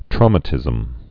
(trômə-tĭzəm, trou-)